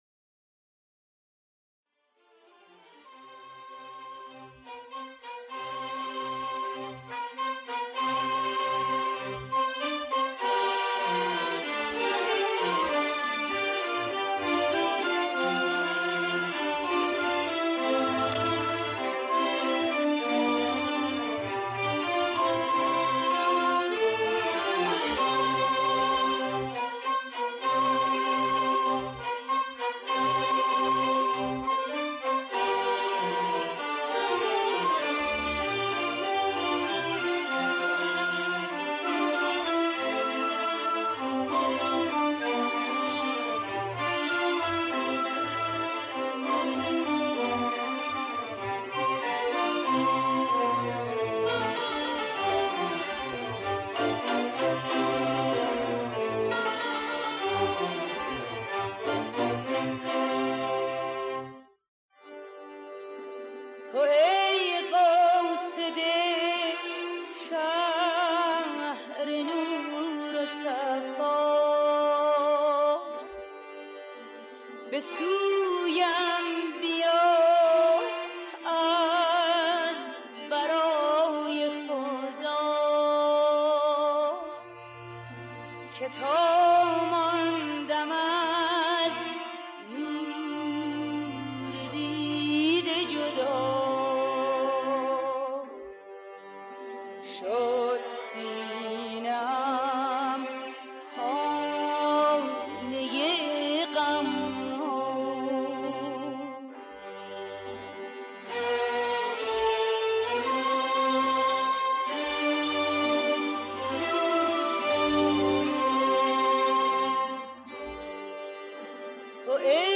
دستگاه: همایون
آواز: بیات ترک